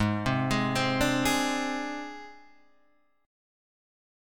G#Mb5 Chord